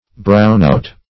brownout \brown"out\ n.